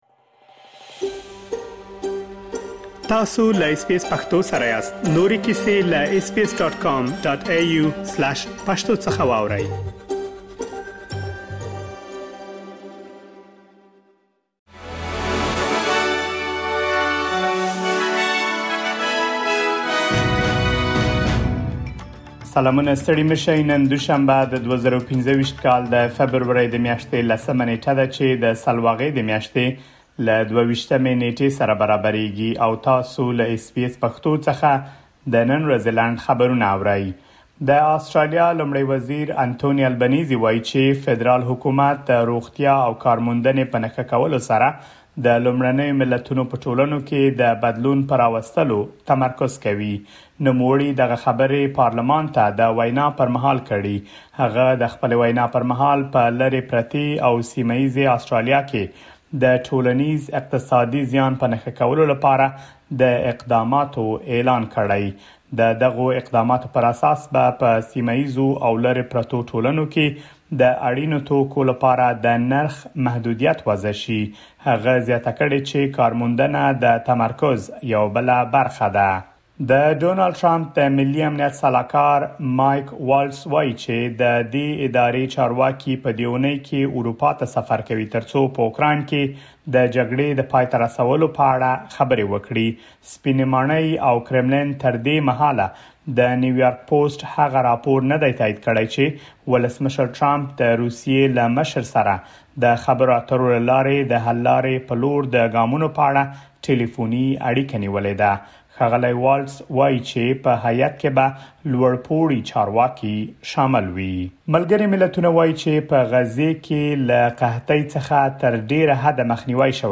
د اس بي اس پښتو د نن ورځې لنډ خبرونه | ۱۰ فبروري ۲۰۲۵
د اس بي اس پښتو د نن ورځې لنډ خبرونه دلته واورئ.